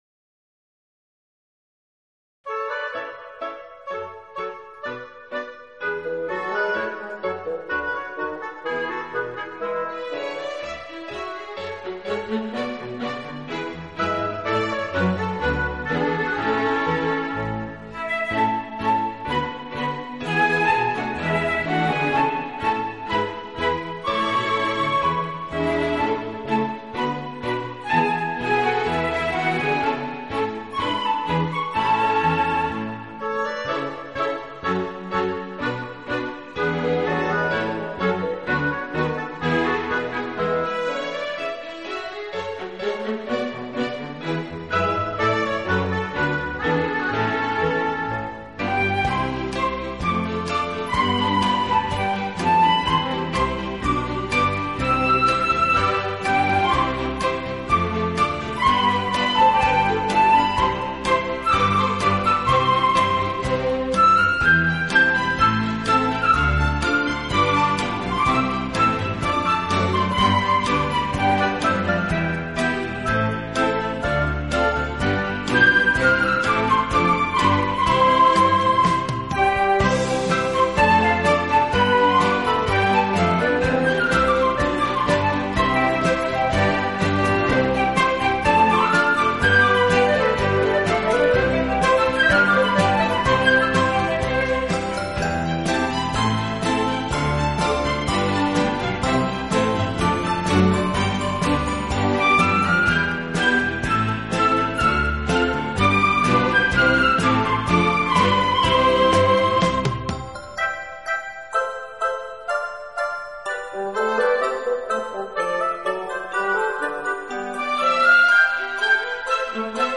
类别：器乐独奏曲